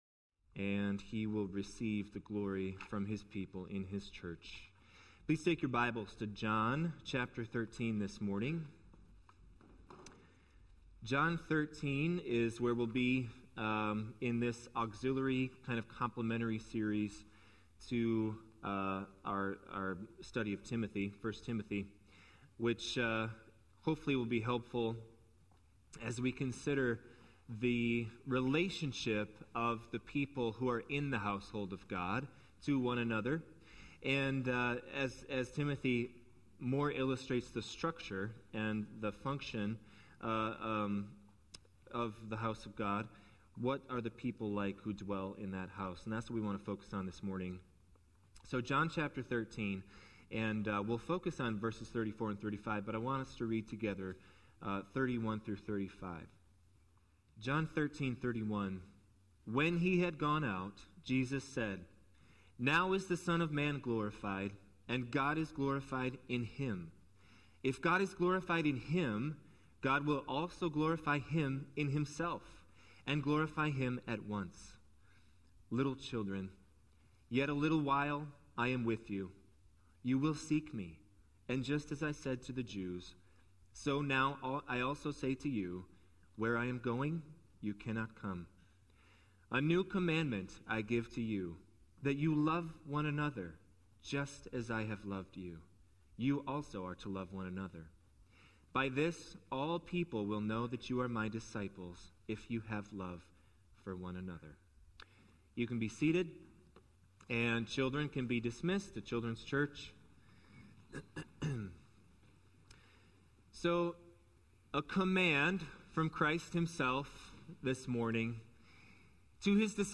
Sermons Archive - Immanuel Baptist Church - Wausau, WI